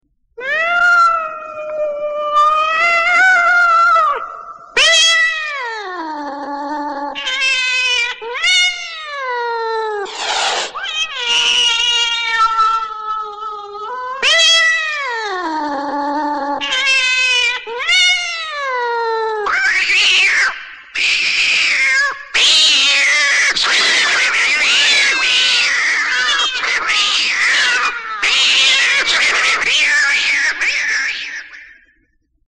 DisneyCatFight.mp3